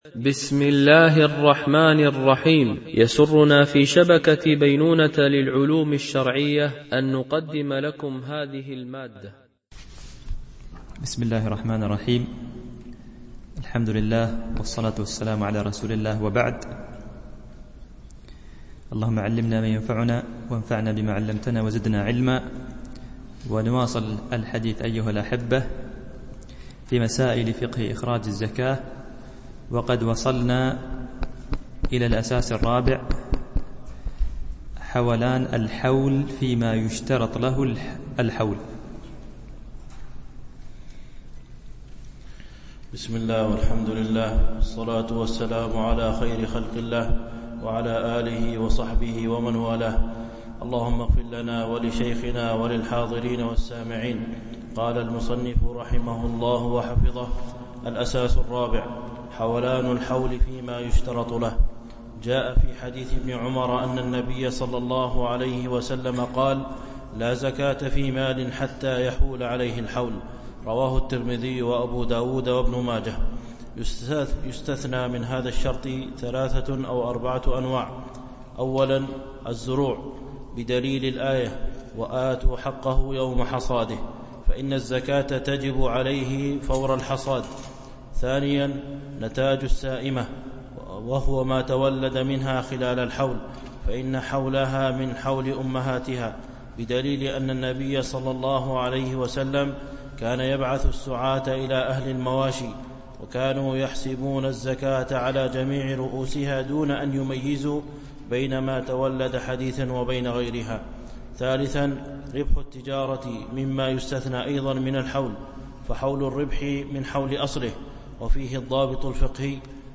دورة علمية شرعية، بمسجد أم المؤمنين عائشة - دبي (القوز 4)